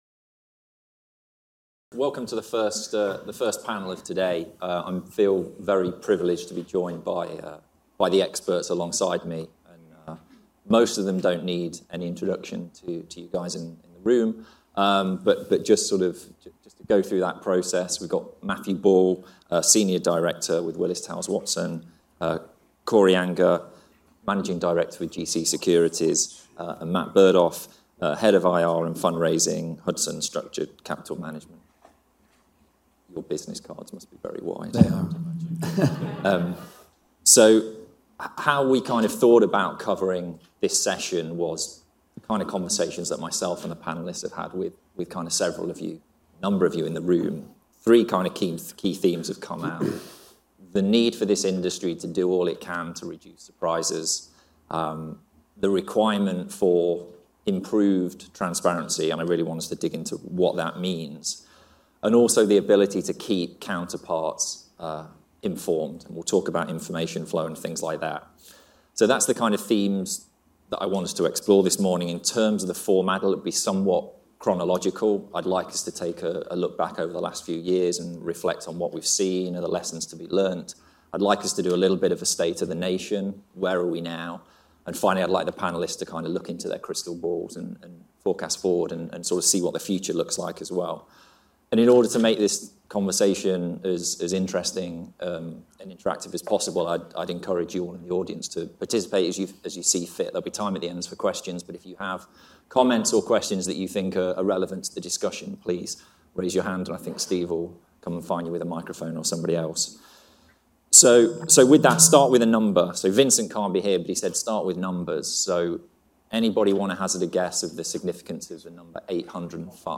At our recent Artemis ILS NYC 2022 conference in New York, speakers in our first session discussed the need to deliver a more predictable experience, where possible, to stakeholders such as insurance-linked securities (ILS) investors and counterparties.
This is the first session from our Artemis ILS NYC 2022 conference, held in New York City on April 22nd, 2022.